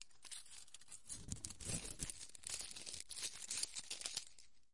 皱纹纸
描述：纸张皱折的声音
标签： 弄皱 起皱 起皱
声道立体声